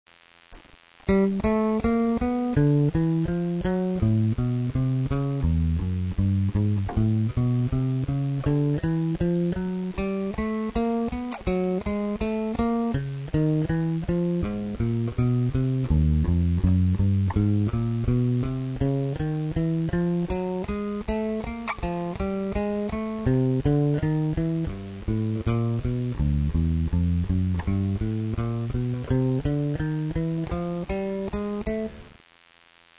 rythme à appliquer:  croche ou double croche avec un tempo donné par le métronome